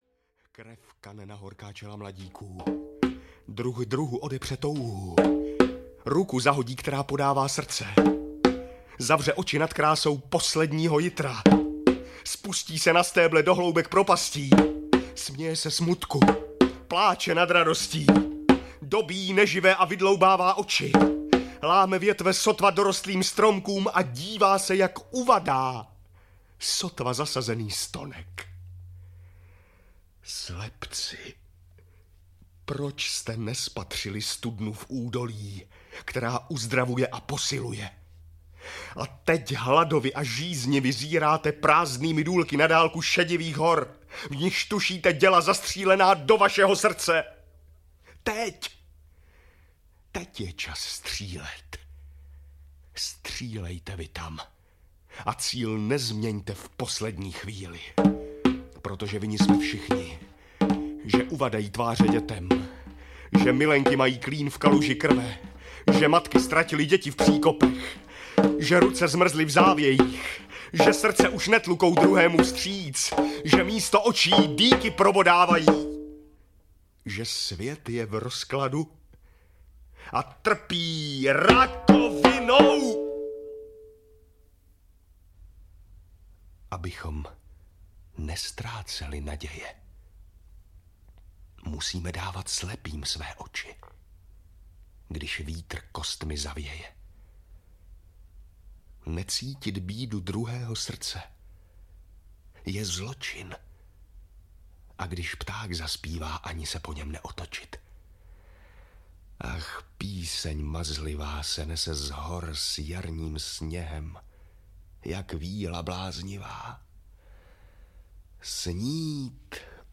Celostátní přehlídka uměleckého přednesu Neumannovy Poděbrady vycházela od roku 1974 na deskách Supraphonu.